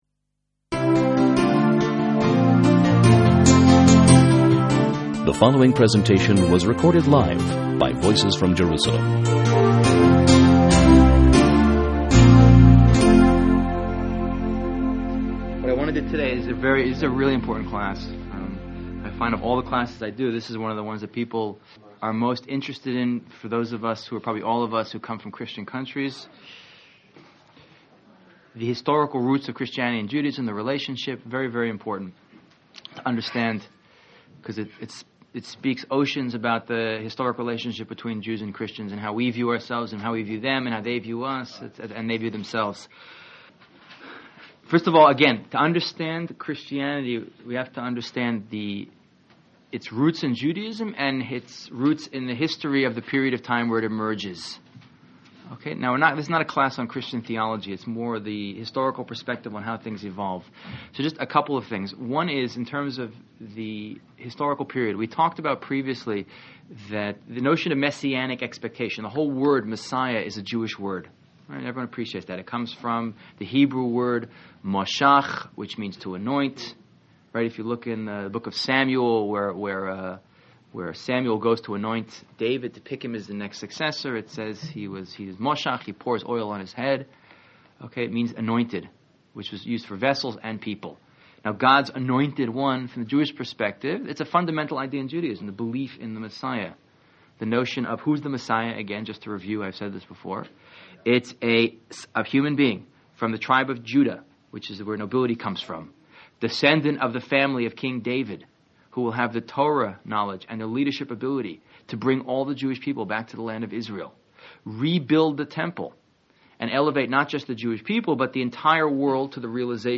Contribute: Add your Summary, Outline or Thoughts on this Lecture Commenting is not available in this channel entry.